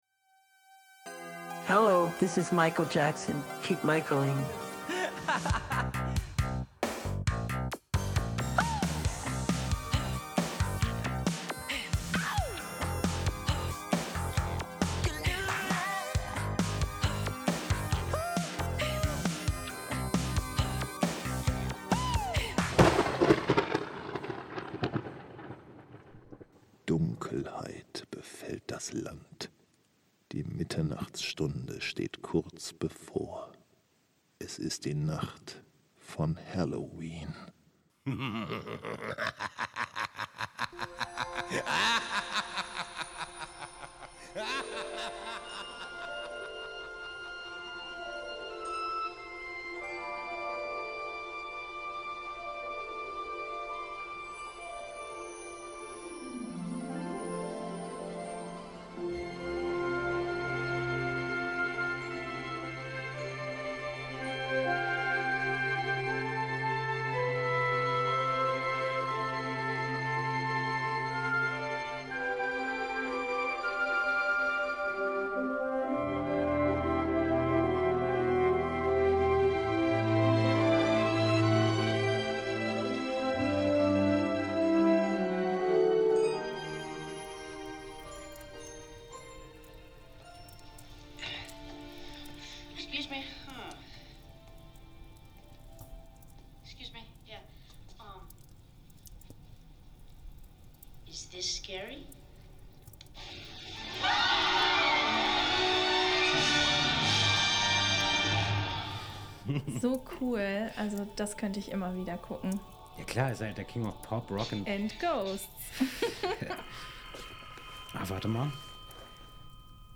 60-ein-halloweenhoerspiel-wie-kein-anderes-stereoversion-mmp.mp3